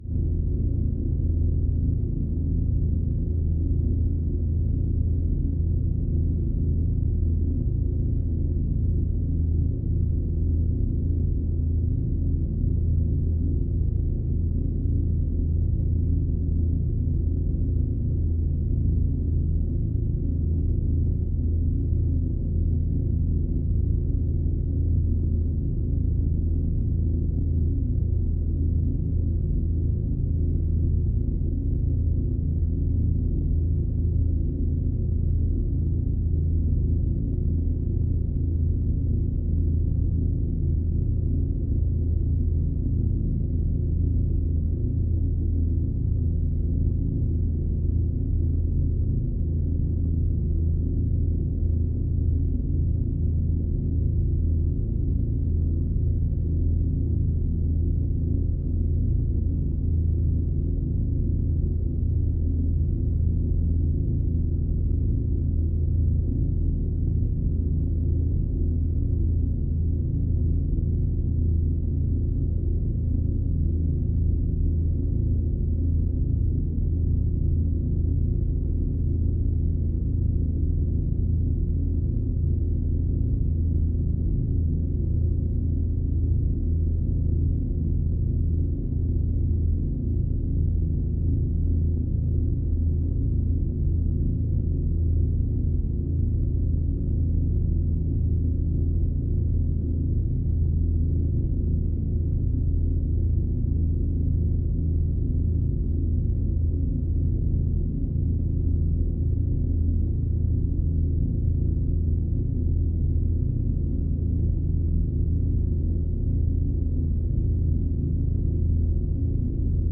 Scifi, Energy, UFO Force Field, Low, Static Roar SND73775.wav